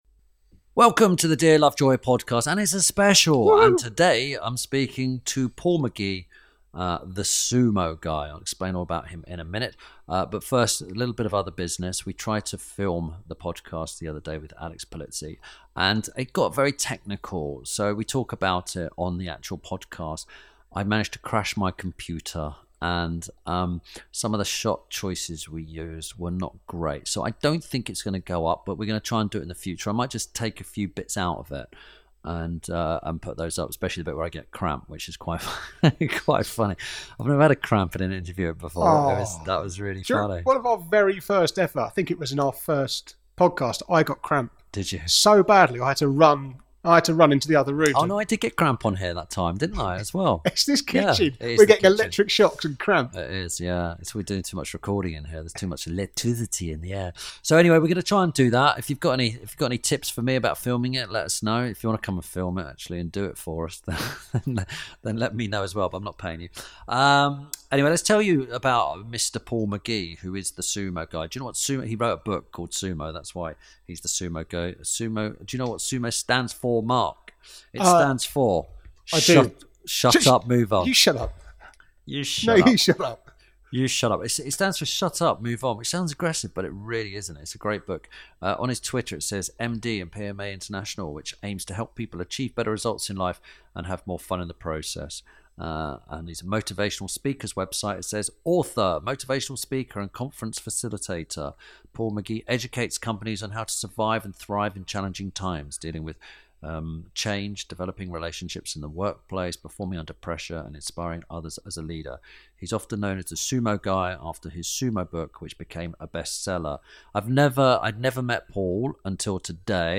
INTERVIEW SPECIAL.